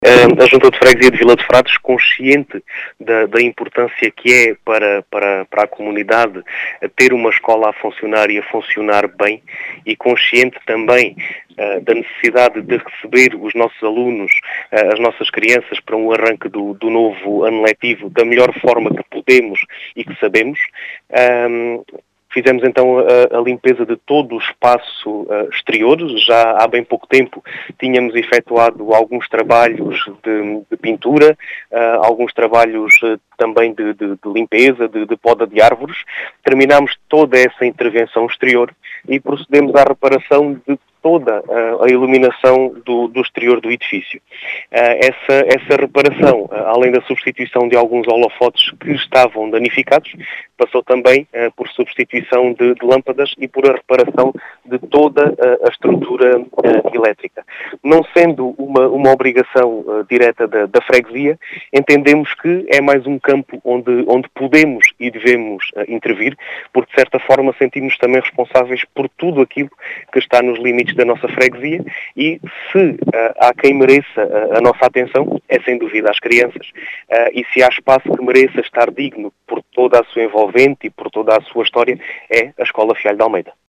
As explicações são de Diogo Conqueiro, presidente da Junta de Freguesia de Vila de Frades, que realçou a importância destes trabalhos efetuados.